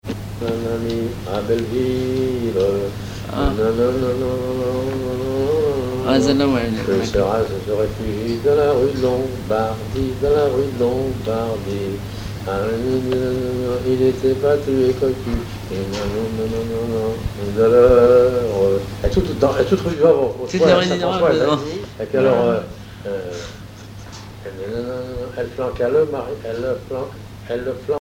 Genre énumérative
Chansons et commentaires
Pièce musicale inédite